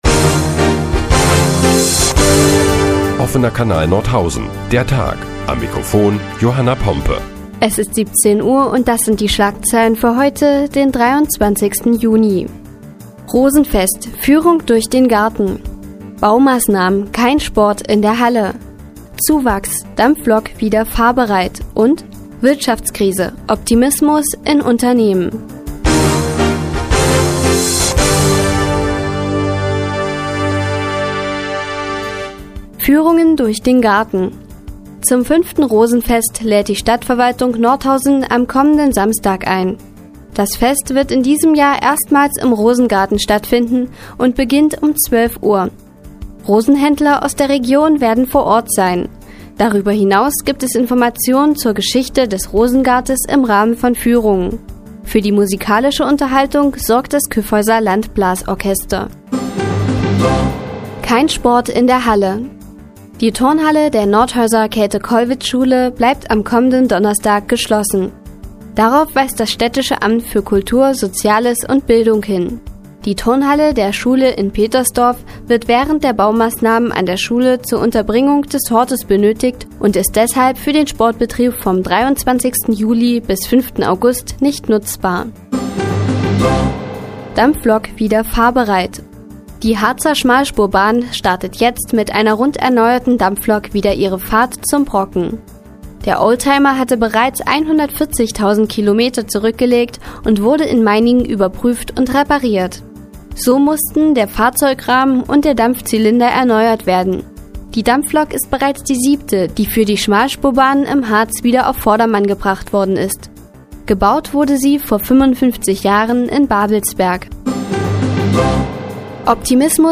Die tägliche Nachrichtensendung des OKN ist nun auch in der nnz zu hören. Heute geht es unter anderem um das anstehende Rosenfest und die restaurierte Dampflok der Harzer Schmalspurbahn.